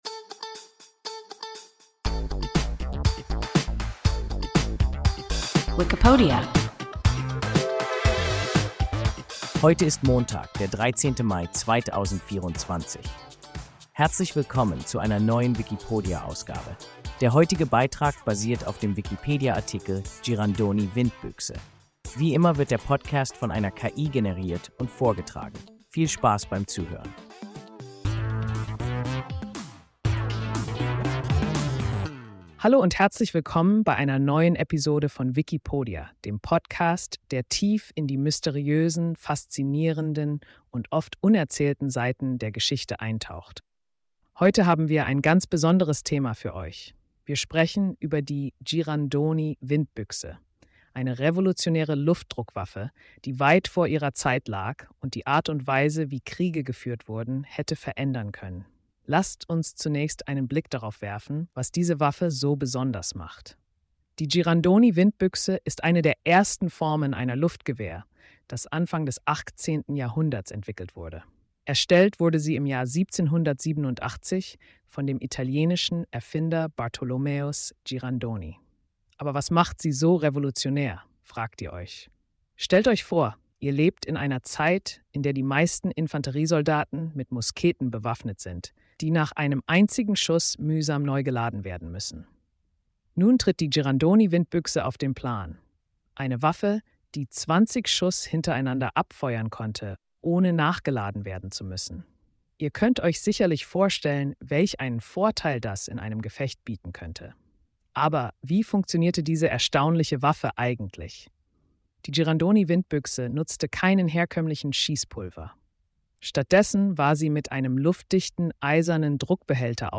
Girandoni-Windbüchse – WIKIPODIA – ein KI Podcast